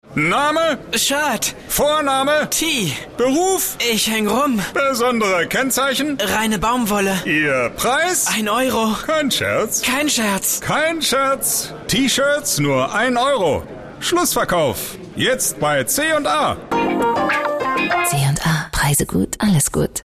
deutscher Profi Sprecher. Off-Sprecher, Werbesprecher, Stationvoice
Kein Dialekt
Sprechprobe: eLearning (Muttersprache):